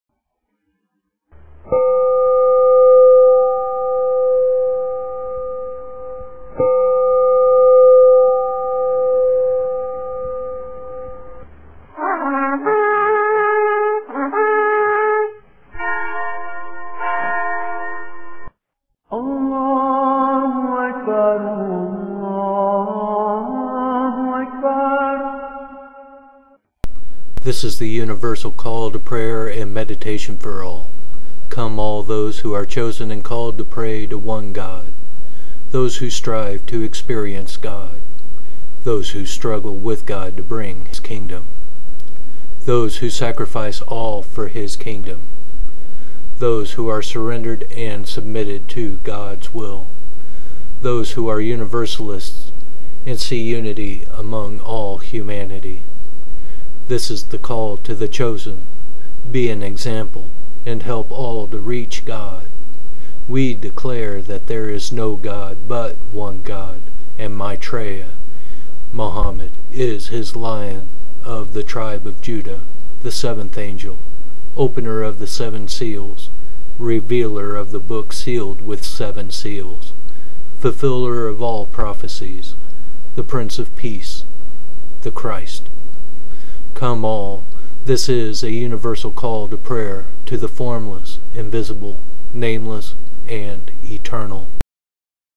Now we have decided to make this call more universal and use an audio which combines sounds from the four major religions on earth (Mystical Paths, Judaism, Christianity and Islam) to Call to Prayer.
2), we read the words we now have in the Mission as the "Universal Call to Prayer."